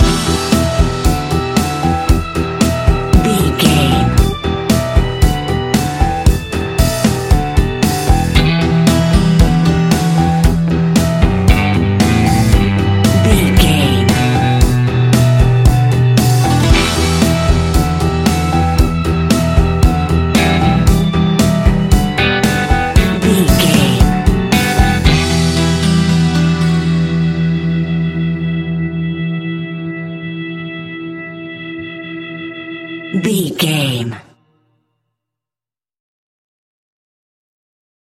Aeolian/Minor
pop rock
indie pop
fun
energetic
uplifting
drums
bass guitar
piano
electric guitar